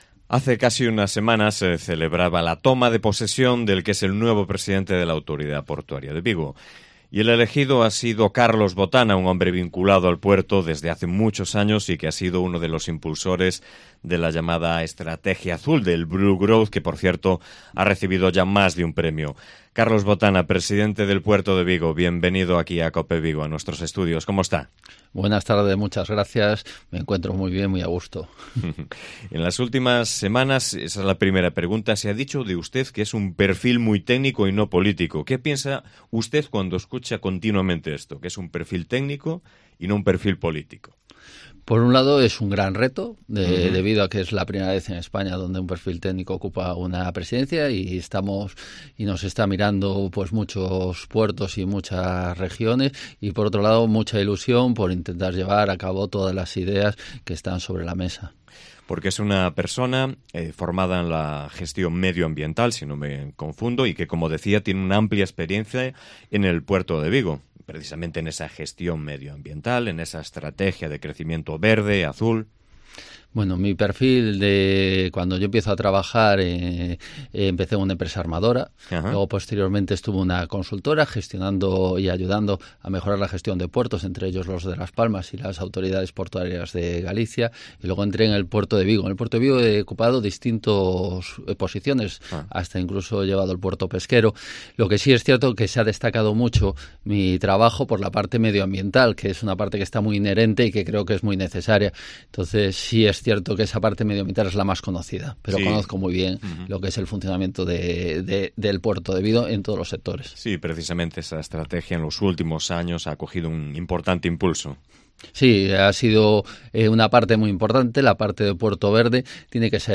Entrevista con Carlos Botana, presidente de la Autoridad Portuaria de Vigo